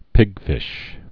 (pĭgfĭsh)